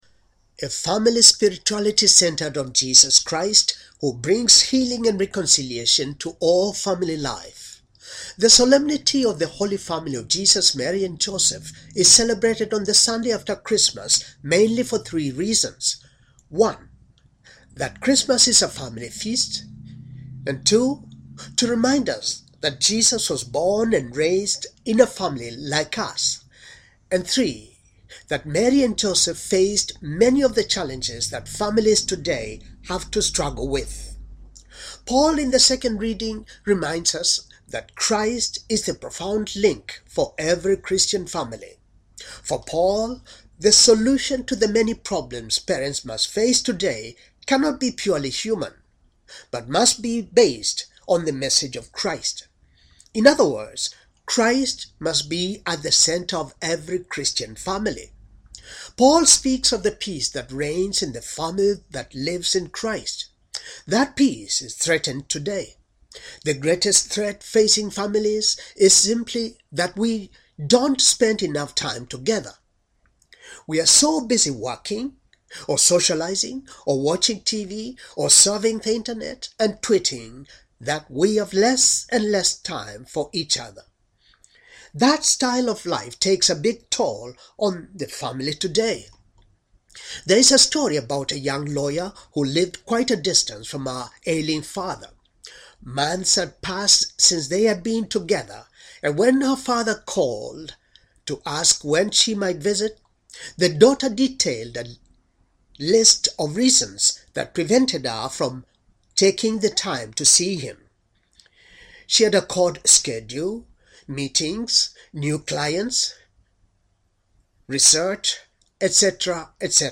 homily for the solemnity of the Holy Family, Jesus, Mary and Joseph